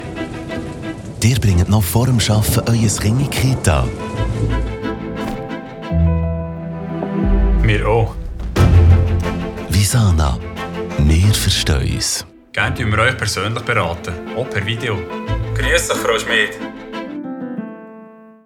Werbung Schweizerdeutsch (BE)
Sprecher mit breitem Einsatzspektrum.